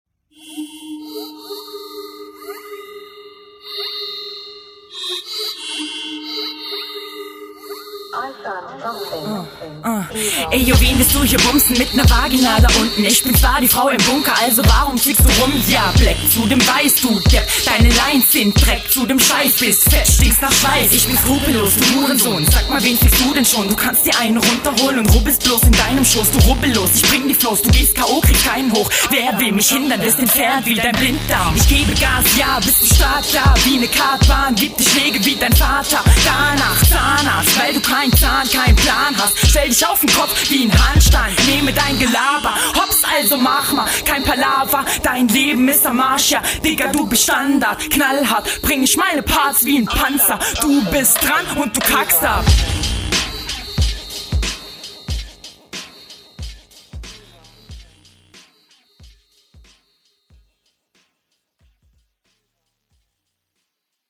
Ja also hier gefällst du mir direkt richtig gut, deine Stimme kommt schön battlemäßig und …
kommt mir so vor als ob du auf den aggresiveren beat ,besser on point kommst …
Stimme geht schön aggressiv nach vorne, auch eine gute Möglichkeit den Beat zu nehmen.